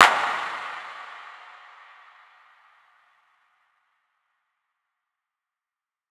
80's Transition Clap.wav